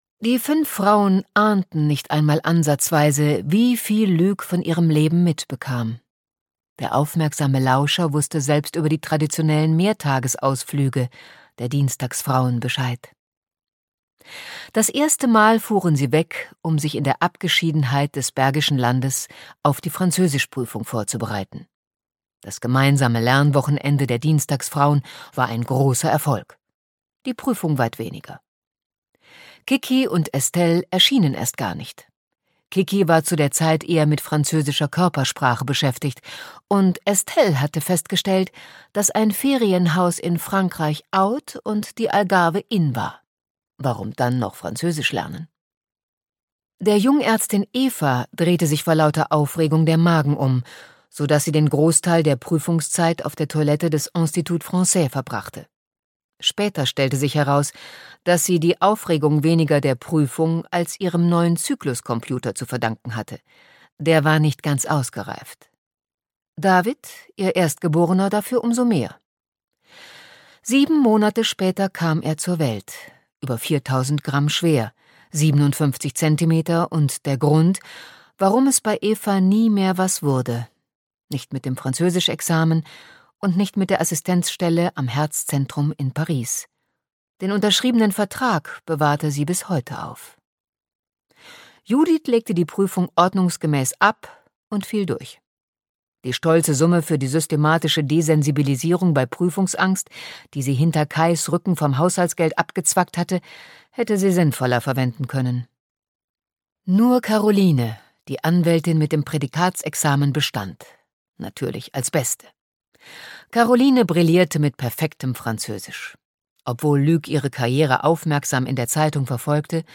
Die Dienstagsfrauen - Monika Peetz - Hörbuch